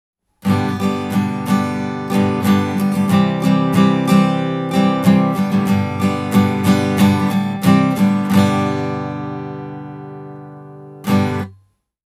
Seuraavissa audiopätkissä esitetään Zoomin stereoasetuksien vaikutusta akustisen kitaran äänityksessä. Q2HD:n etäisyys kitaraan pysyy koko ajan samana: